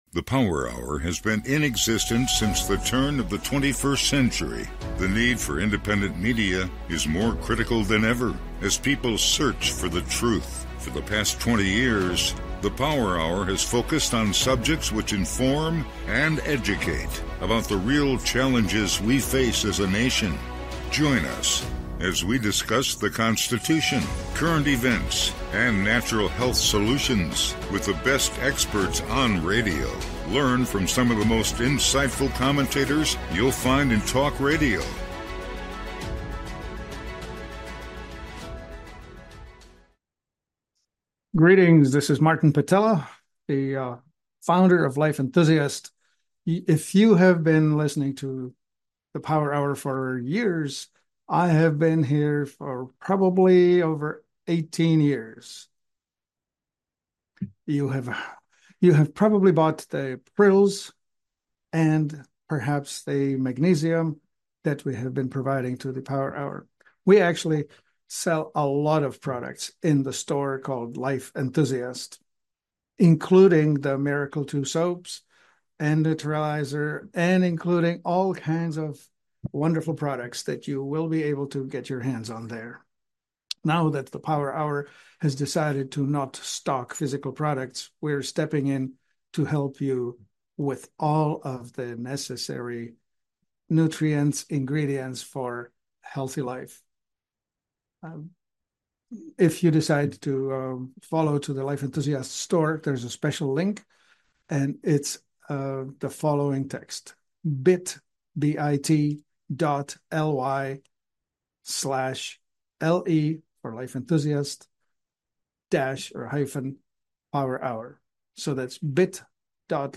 The Power Hour with Guest Hosts of Distinction